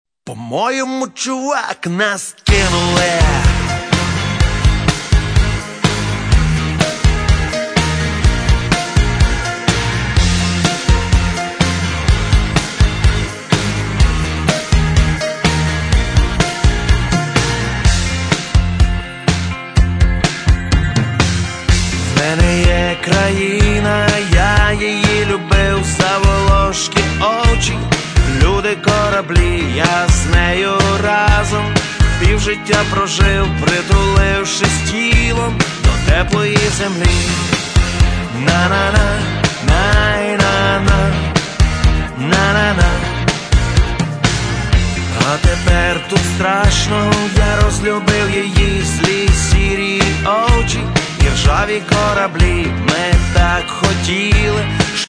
Початок » CDs» Рок Мій аккаунт  |  Кошик  |  Замовити